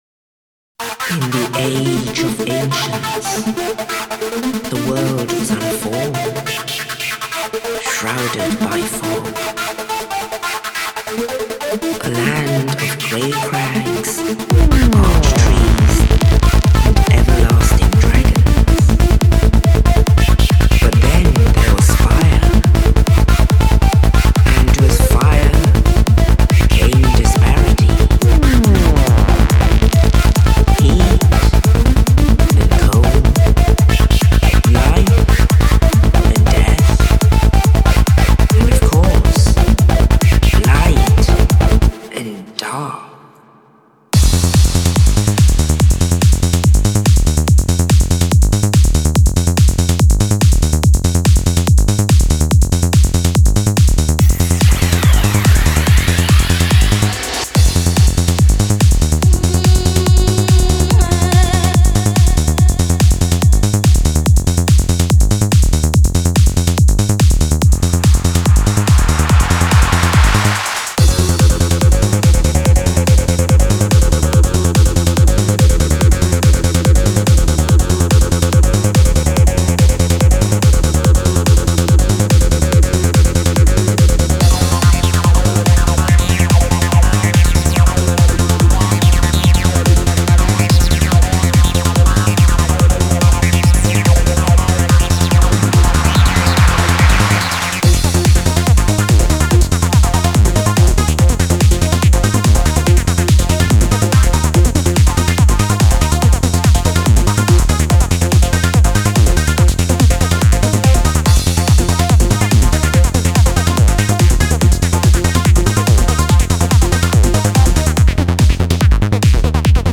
An impressive collection of Psytrance sounds.